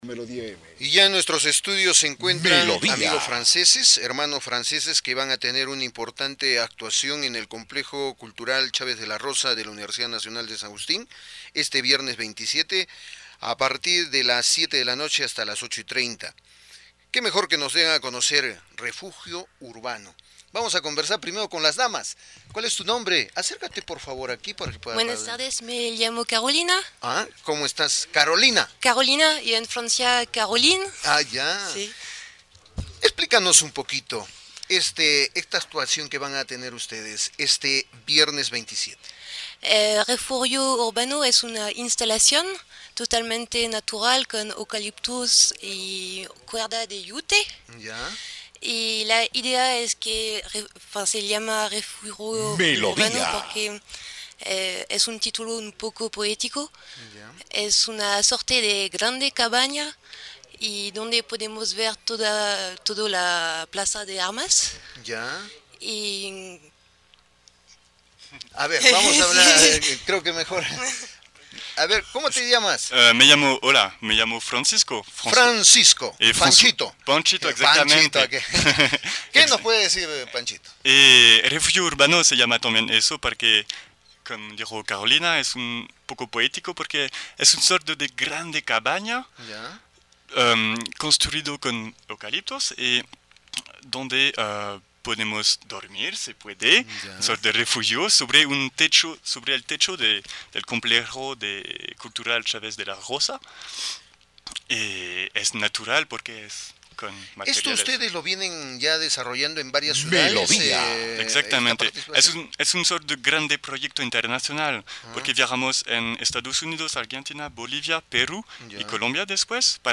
Interview Melodia
ENTREVISTA RADIO MELODIA
entrevistaradiomelodiamodifiee.mp3